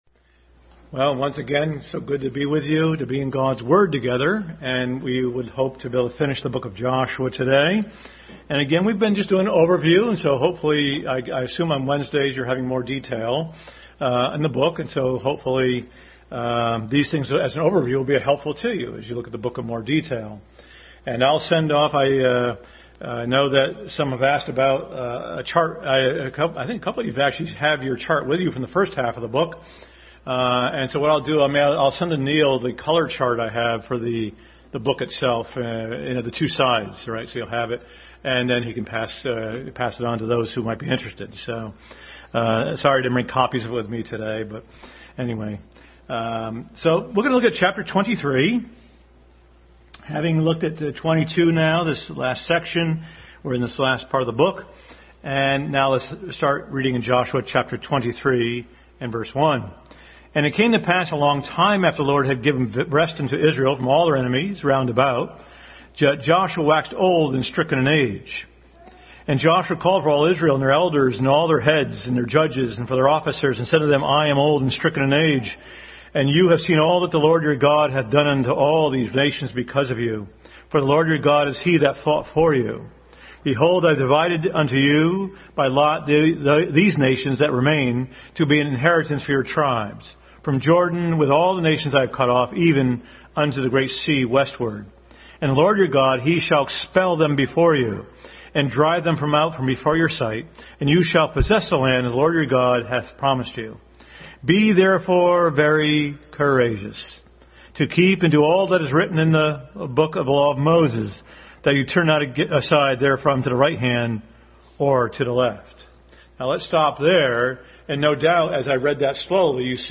Passage: Joshua Chapters 23-24 Service Type: Family Bible Hour